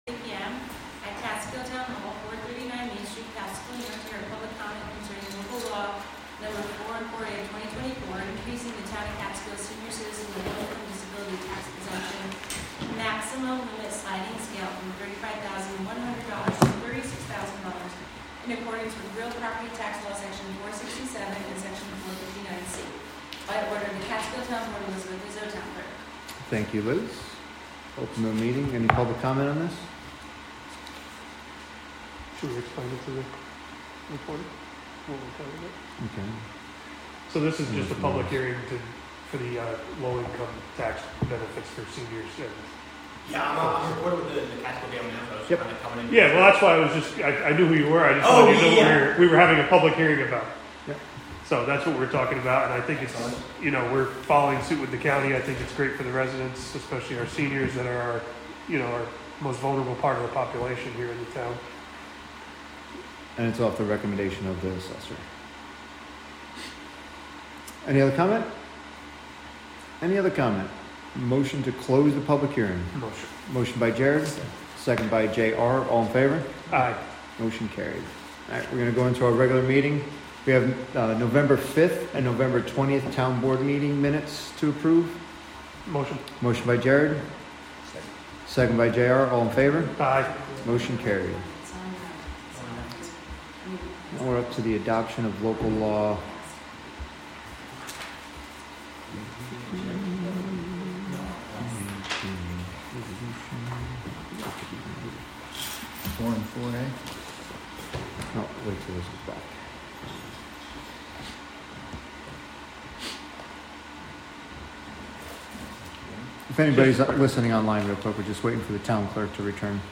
Live from the Town of Catskill: December 3, 2024 Catskill Town Board Meeting (Audio)